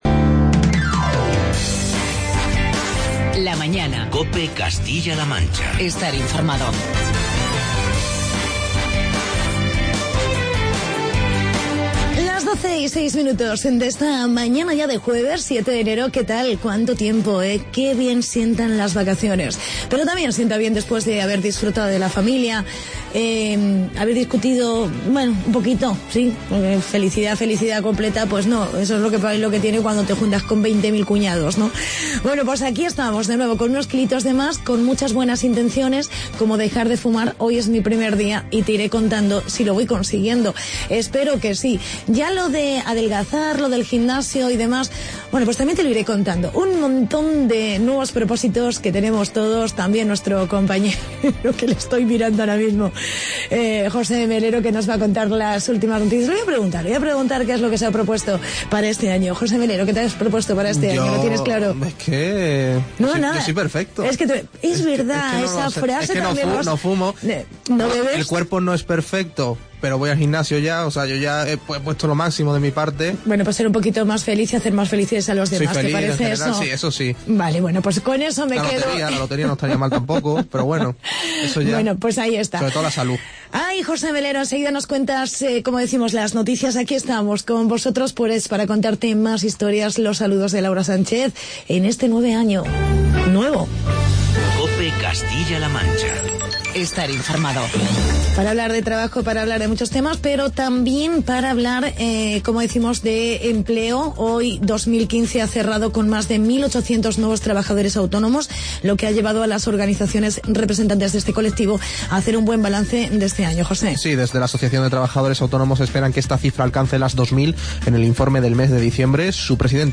Entrevista con el concejal de Almagro